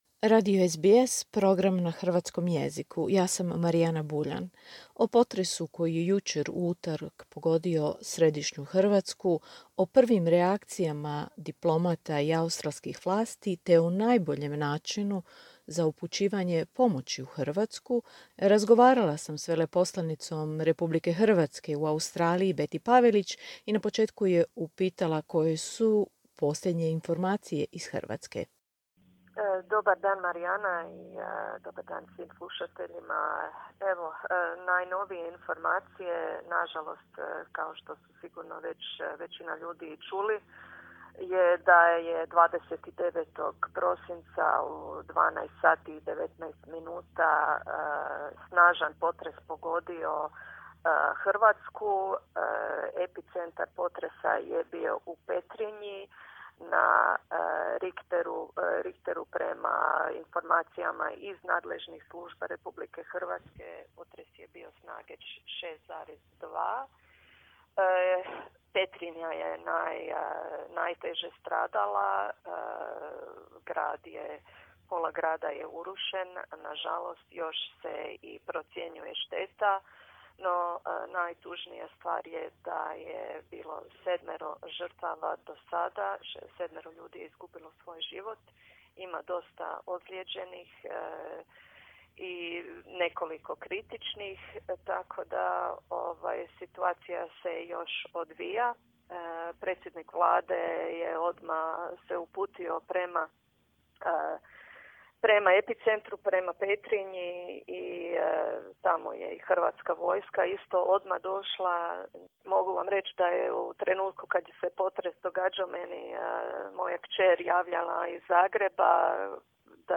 Veleposlanica RH Betty Pavelić govori o najnovijim informacijama o posljedicama potresa u središnjoj Hrvatskoj, prvim porukama potpore australskih vlasti i diplomata u Australiji, te o najboljim načinima upućivanja pomoći.